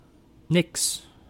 Ääntäminen
IPA : [dɪsəˈɡɹiəbəɫ]